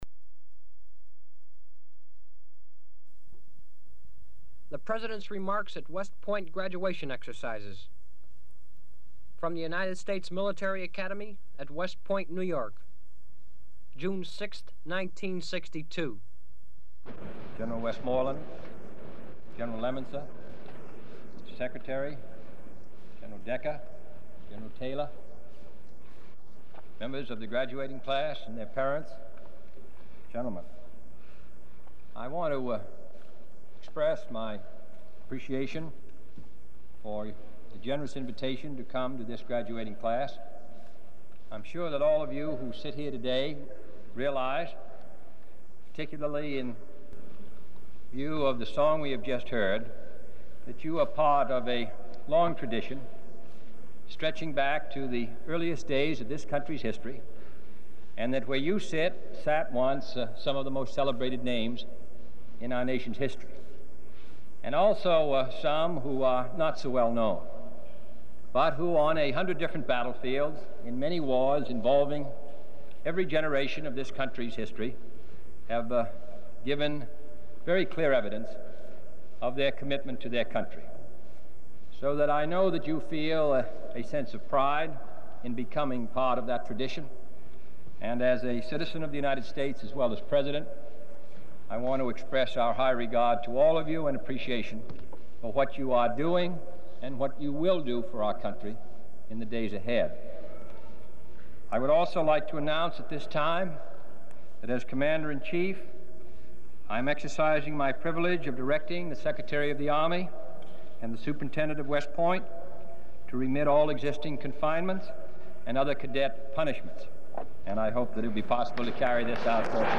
June 6, 1962: Remarks at West Point | Miller Center
Presidential Speeches | John F. Kennedy Presidency